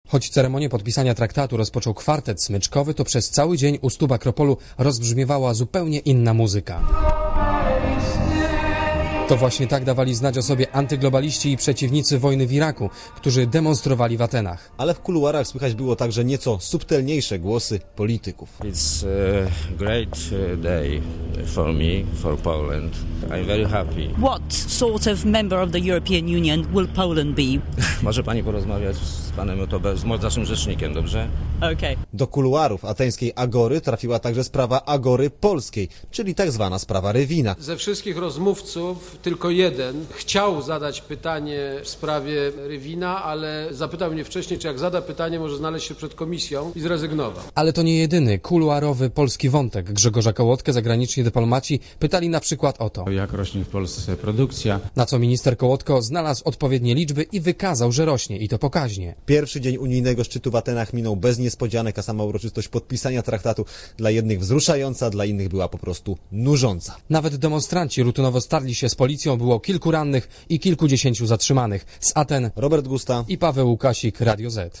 Posłuchaj relacji reporterów Radia Zet (0.5 MB)